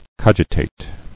[cog·i·tate]